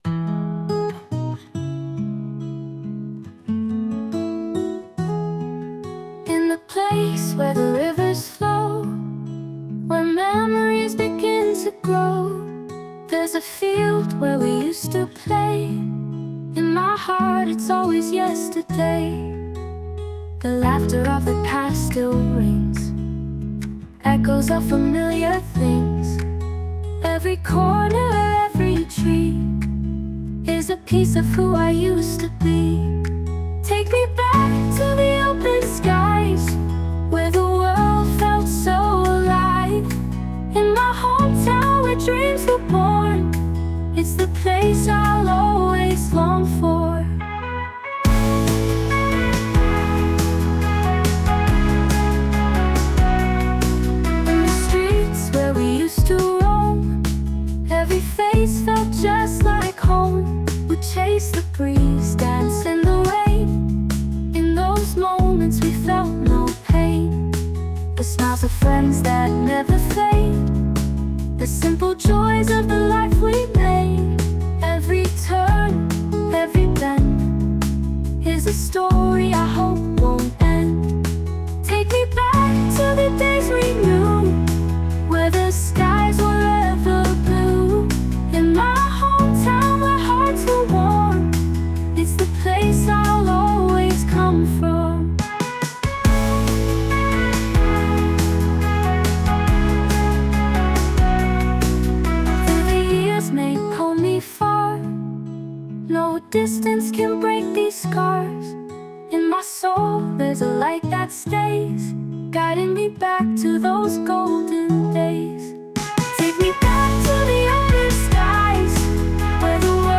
女性ボーカル洋楽 女性ボーカルプロフィールムービーエンドロール
女性ボーカル（洋楽・英語）曲です。